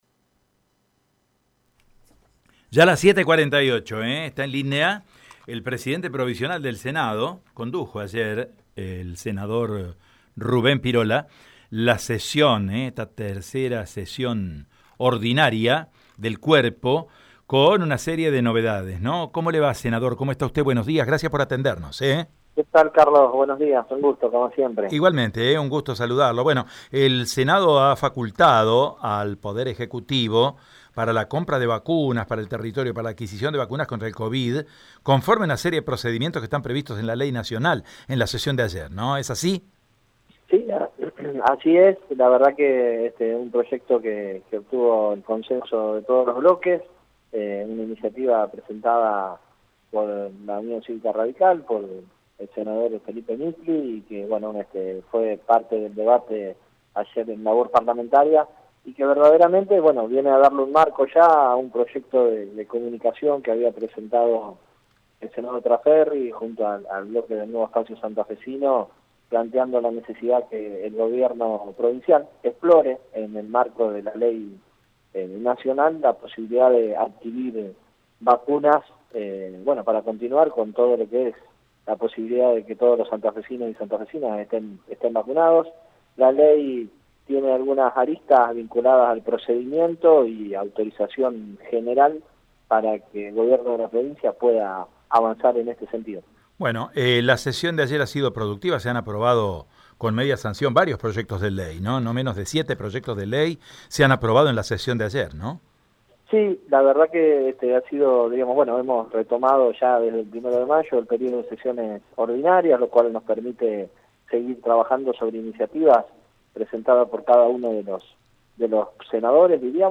En dialogo con Radio EME, Rubén Pirola, presidente Provisional del Senado y senador por Las Colonias, explicó que «el proyecto que obtuvo el consenso de todos los bloques».